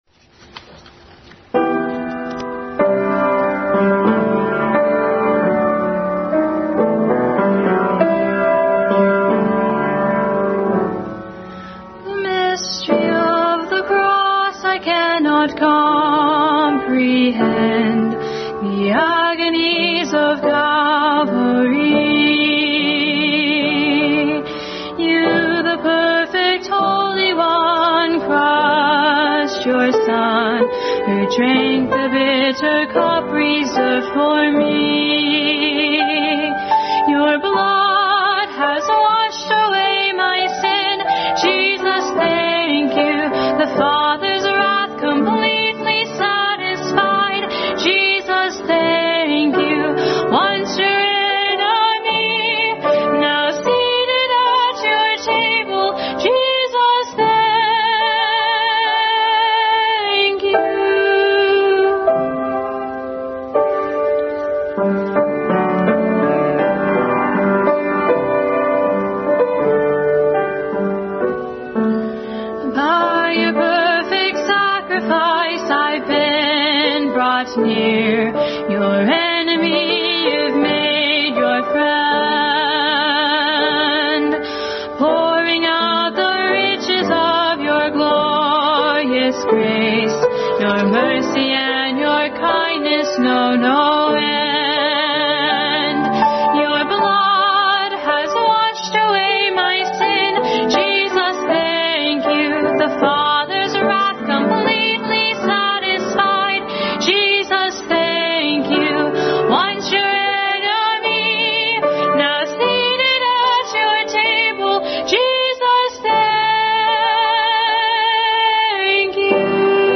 Special Music
2 Timothy 3:14-4:4 Passage: 2 Timothy 3:14-4:4, 3:16, 1 Peter 3:15, Proverbs 4:25 Service Type: Family Bible Hour